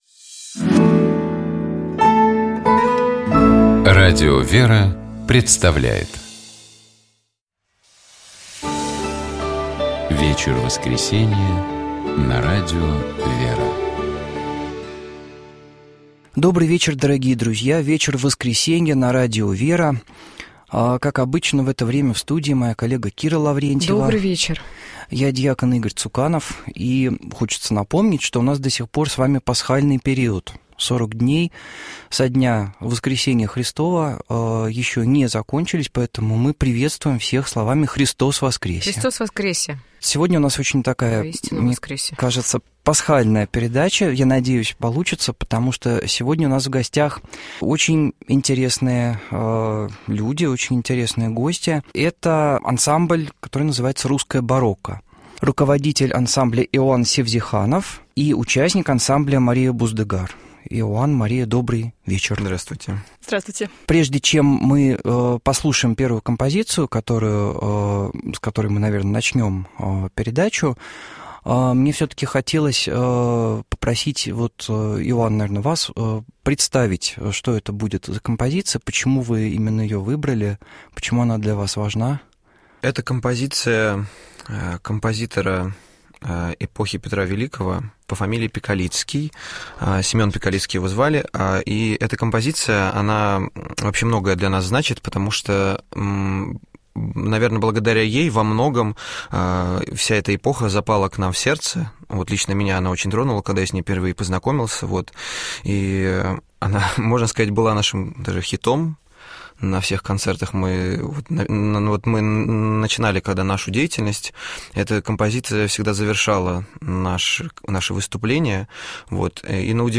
«Вечер воскресенья» на радио ВЕРА.